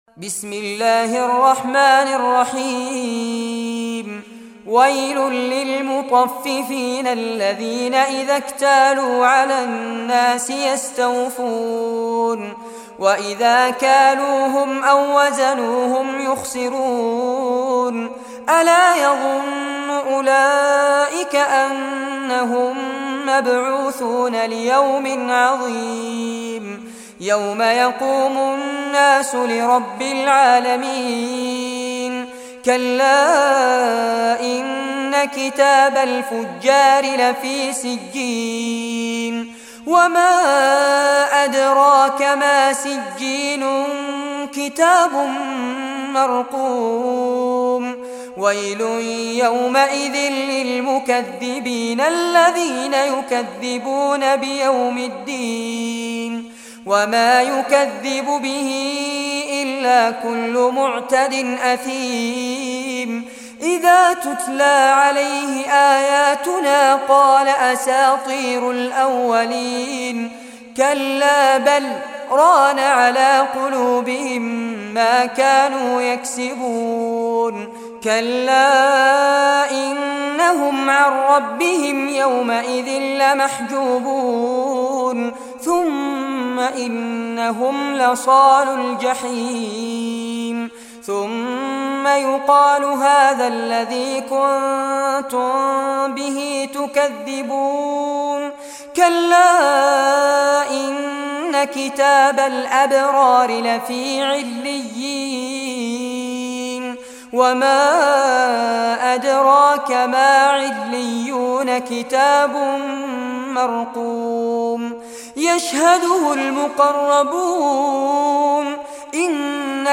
Surah Al-Mutaffifin Recitation by Fares Abbad
Surah Al-Mutaffifin, listen or play online mp3 tilawat / recitation in Arabic in the beautiful voice of Sheikh Fares Abbad.